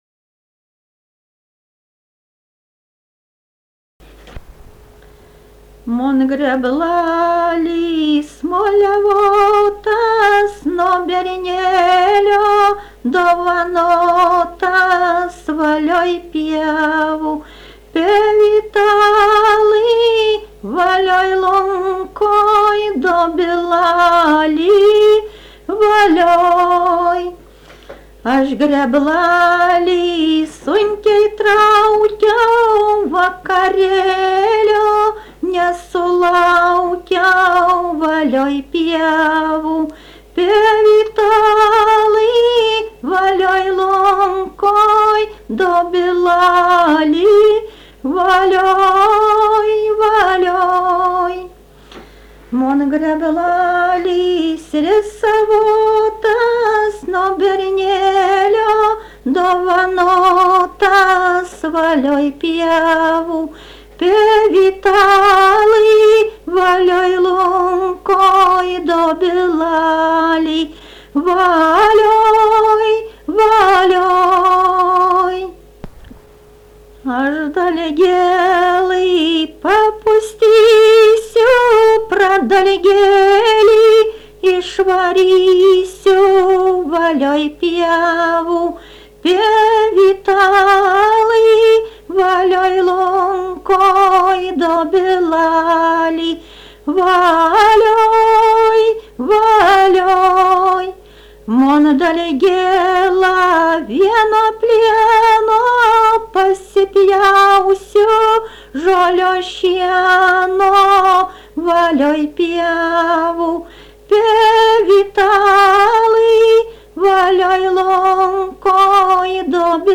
daina, vestuvių
Erdvinė aprėptis Šimonys
Atlikimo pubūdis vokalinis